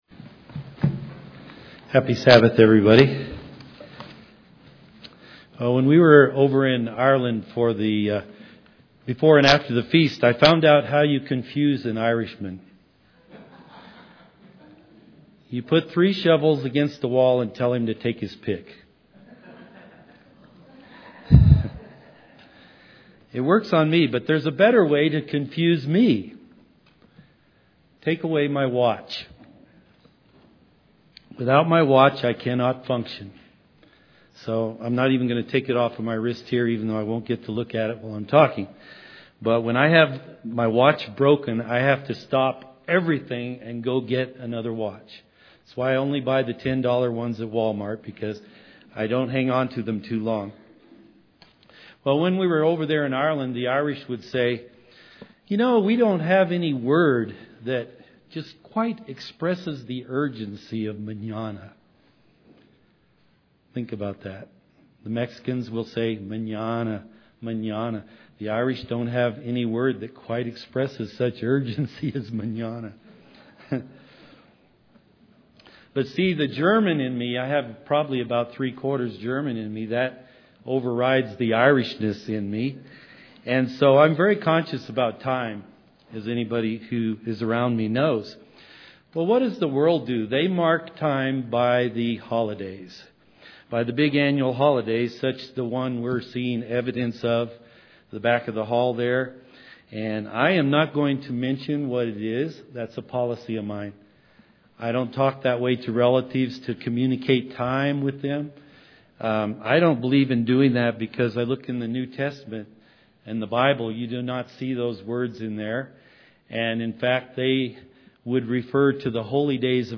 Given in Colorado Springs, CO
Out times are in His hand (Psalm 31:15) UCG Sermon Studying the bible?